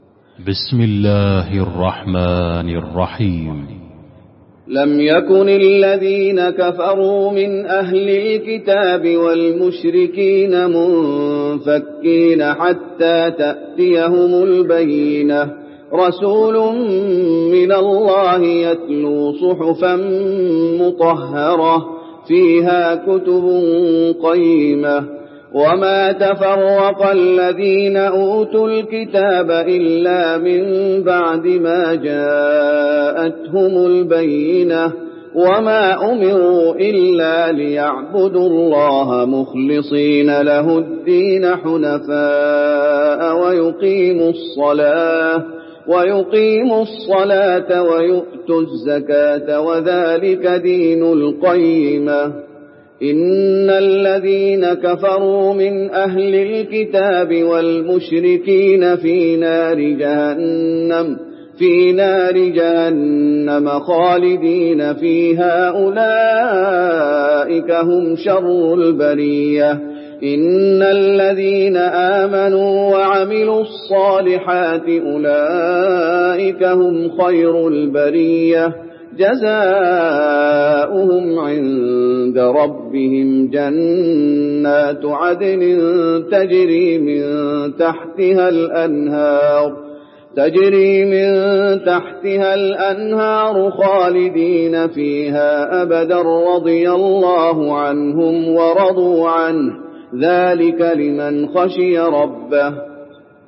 المكان: المسجد النبوي البينة